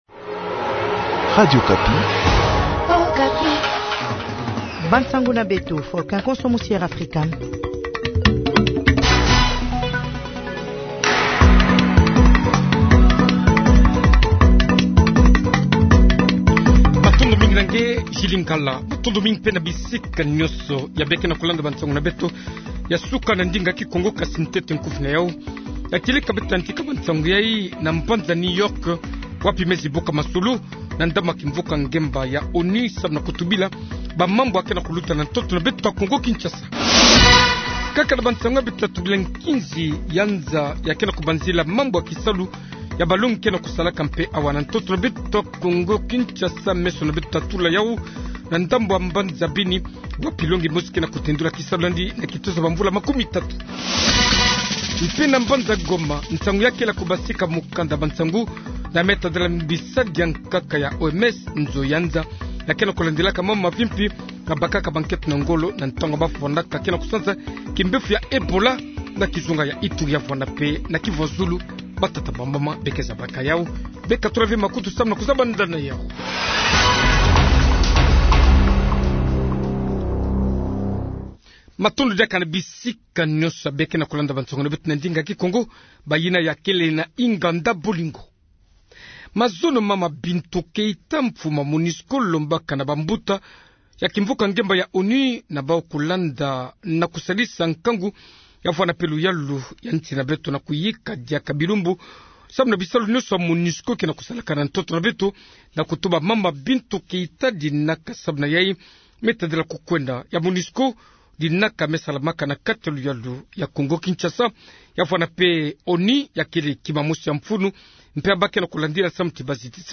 Journal kikongo matin 06 octobre 22021